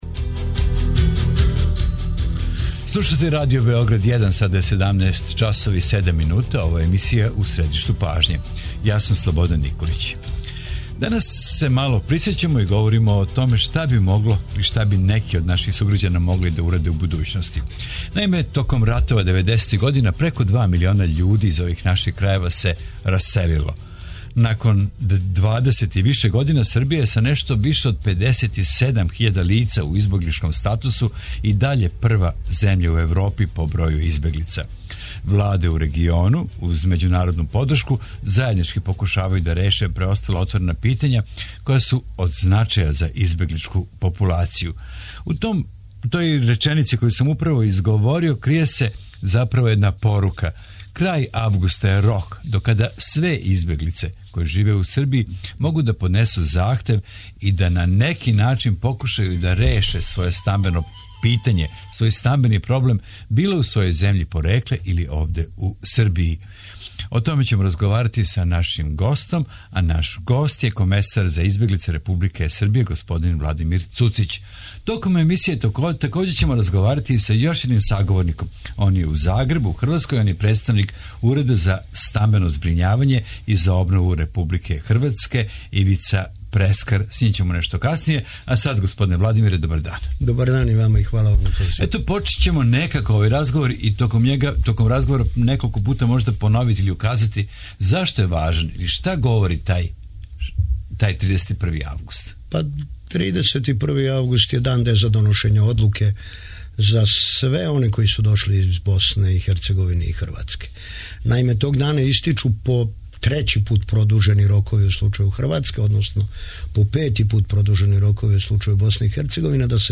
У емисији У средишту пажње тражимо одговор на питање - шта се нуди избеглицама у Србији а шта у Хрватској и Босни и Херцеговини? Такође питамо - како решити стамбено питање? Гост емисије је комесар за избеглице Републике Србије Владимир Цуцић, а током емисије разговараћемо и са представницима Министарства за људска права и избеглице Владе Босне и Херцеговине и са представницима Уреда за стамбено збрињавање и обнову Републике Хрватске.